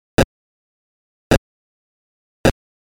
Пшик!
Простейший шумовой ударный звук, низкий отрывистый гулкий шум, выглядит так:
Так как начальный адрес чтения всегда одинаков, характер шума остаётся неизменным при каждом запуске эффекта.
zxsfx_noise.mp3